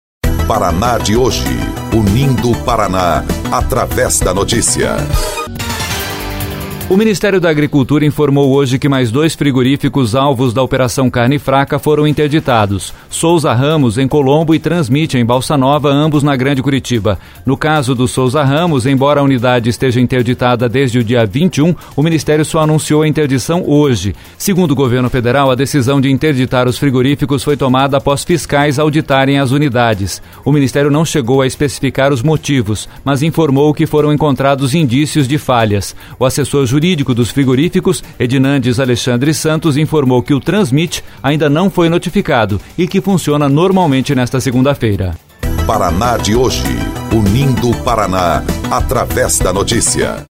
BOLETIM – Ministério anuncia interdição de mais dois frigoríficos no Paraná